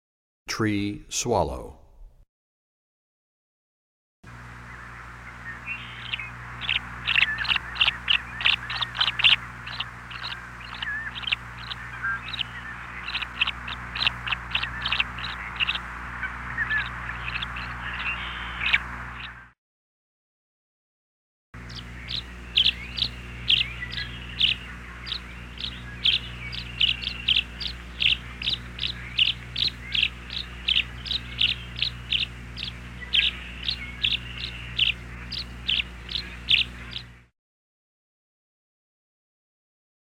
91 Tree Swallow.mp3